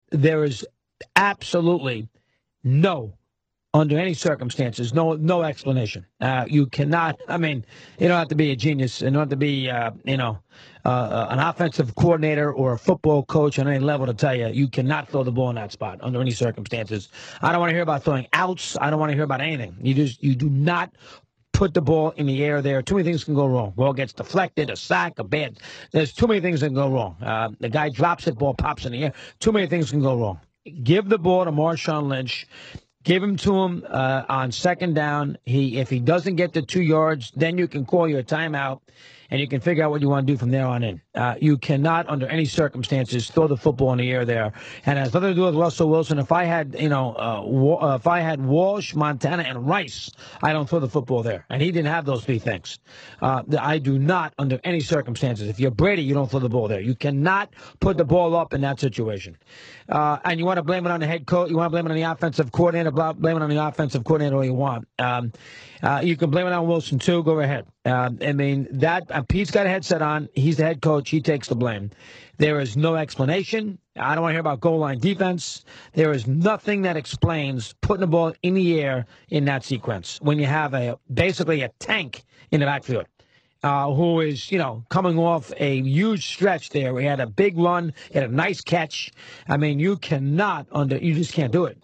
On Monday, Mike and the Dog gave similar takes during opening monologues on their respective radio shows two hours apart.